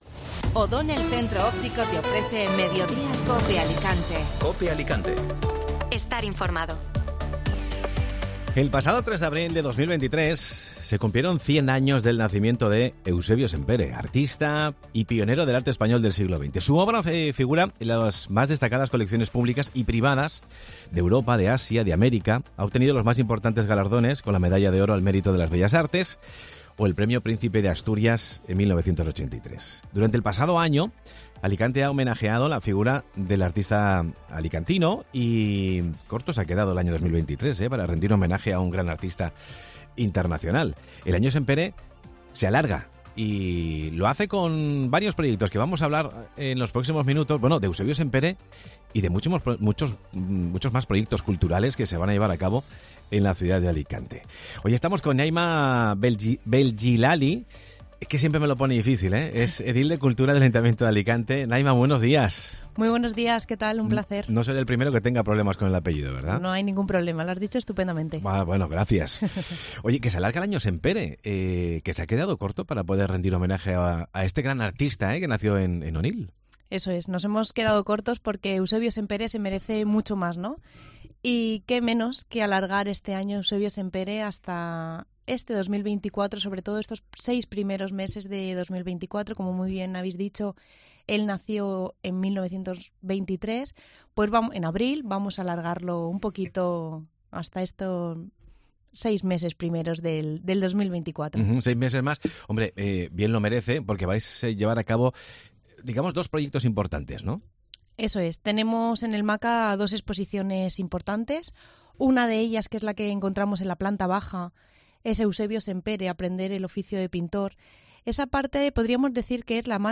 AUDIO: La concejala de Cultura ha avanzado en Mediodía COPE Alicante los diferentes proyectos culturales que se están proyectando en la ciudad.